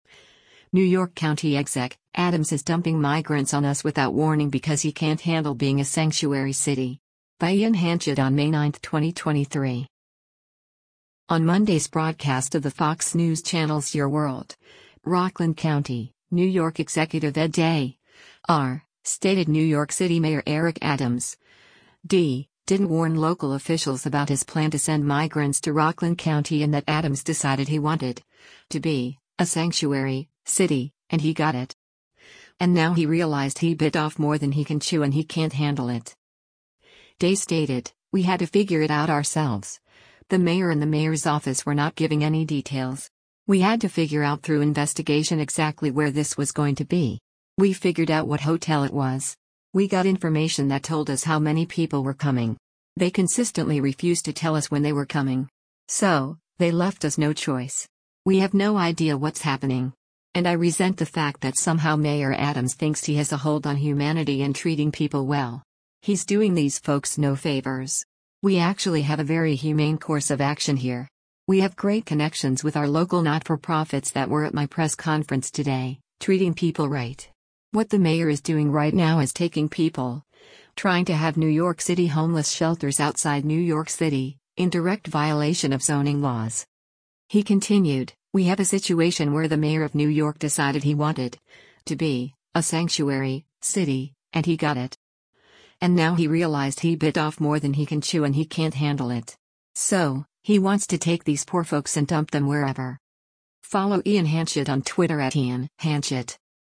On Monday’s broadcast of the Fox News Channel’s “Your World,” Rockland County, New York Executive Ed Day (R) stated New York City Mayor Eric Adams (D) didn’t warn local officials about his plan to send migrants to Rockland County and that Adams “decided he wanted [to be] a sanctuary [city], and he got it. And now he realized he bit off more than he can chew and he can’t handle it.”